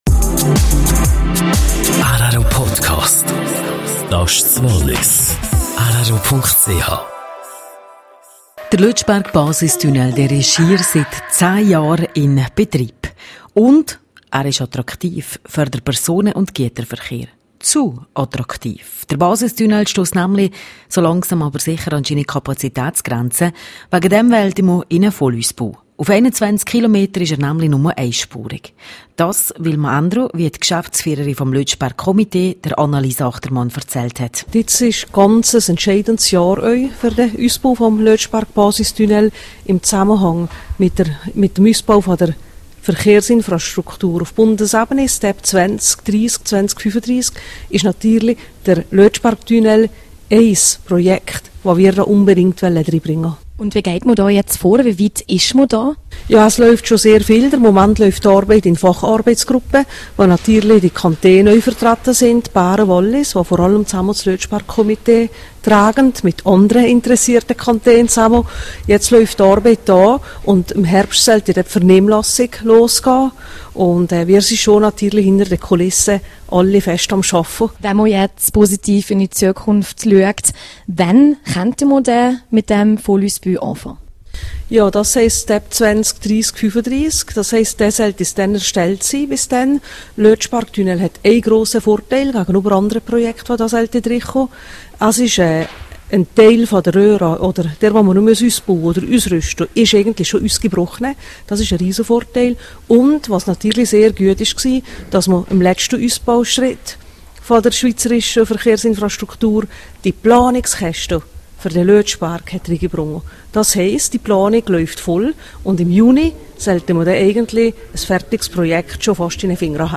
Interview mit Viola Amherd zum Vollausbau des Lötschberg-Basistunnels.